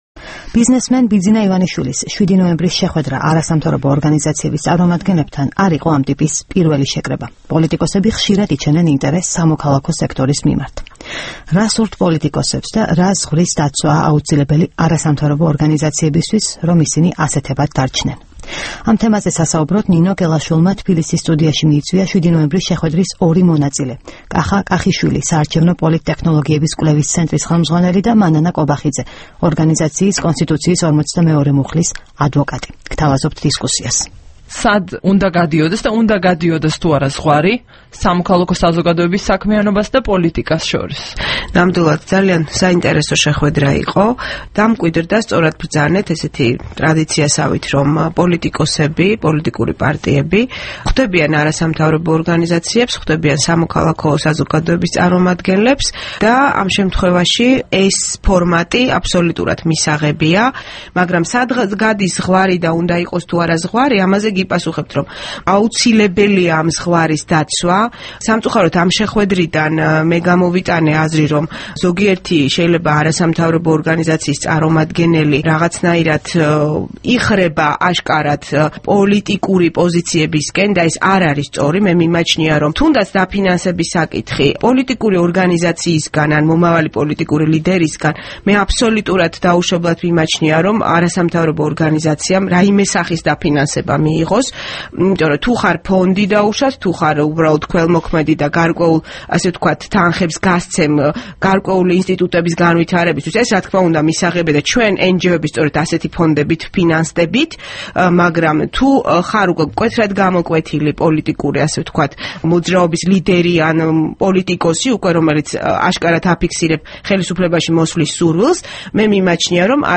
საუბარი